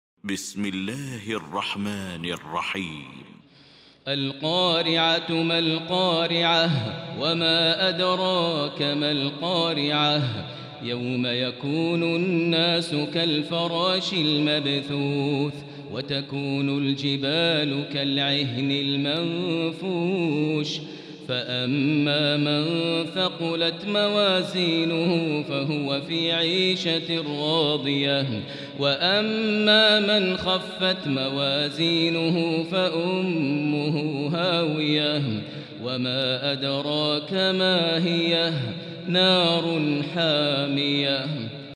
المكان: المسجد الحرام الشيخ: فضيلة الشيخ ماهر المعيقلي فضيلة الشيخ ماهر المعيقلي القارعة